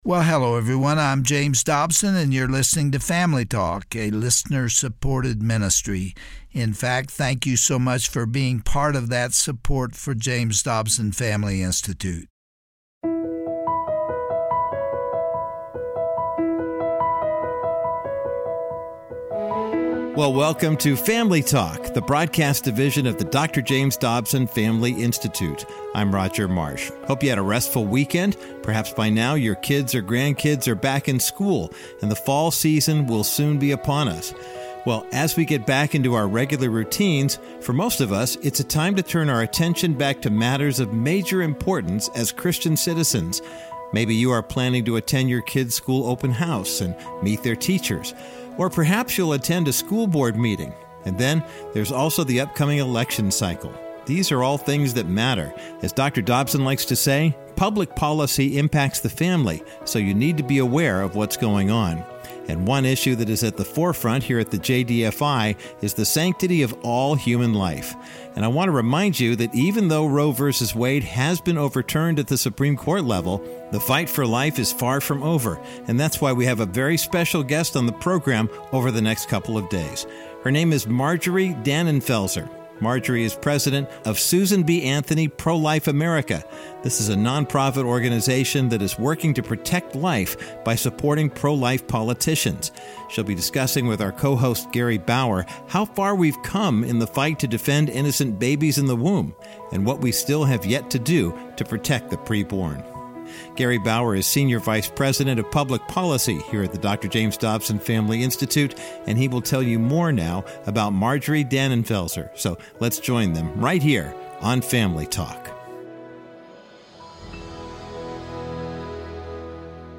On today’s edition of Dr. James Dobson’s Family Talk, Gary Bauer interviews Marjorie Dannenfelser, president of Susan B. Anthony Pro-Life America.